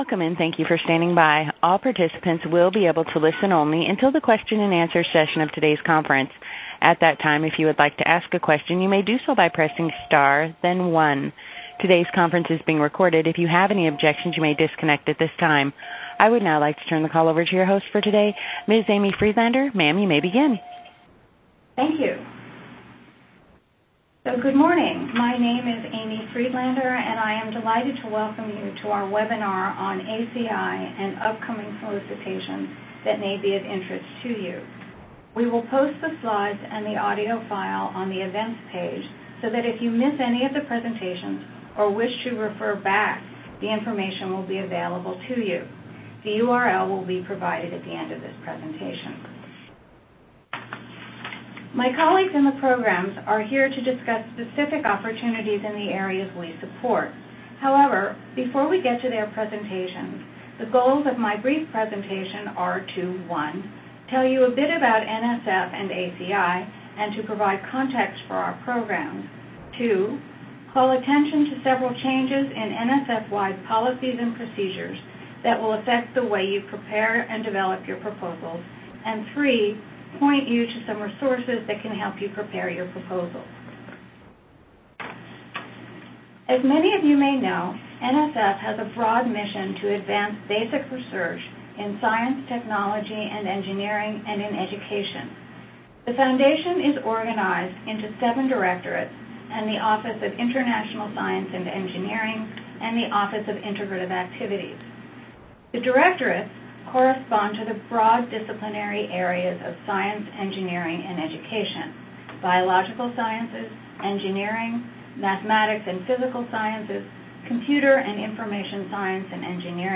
ACI Solicitation Webinar